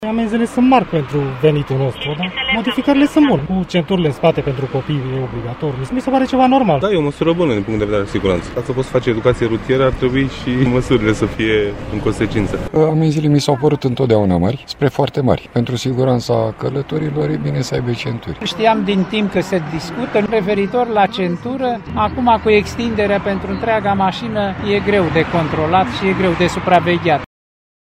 i-a întrebat pe câţiva şoferi ce cred despre aceste modificări: